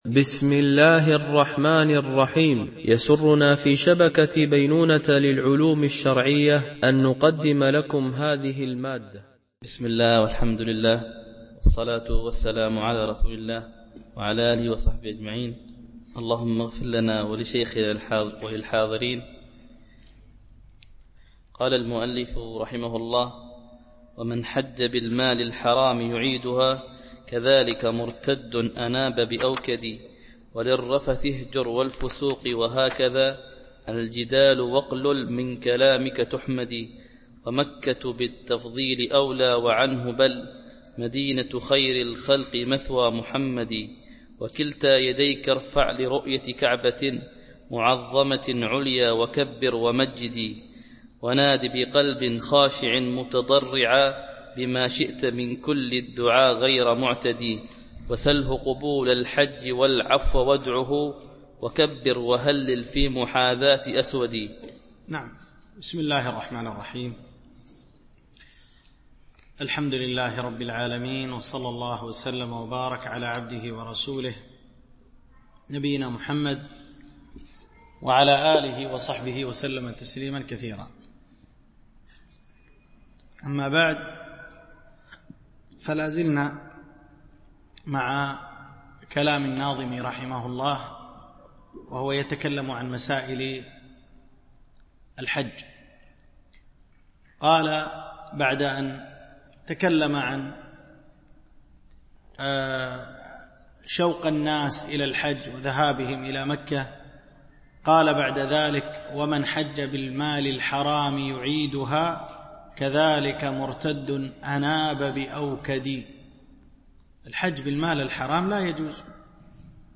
شرح منظومة الآداب الشرعية – الدرس 43 ( الأبيات 659 - 682 )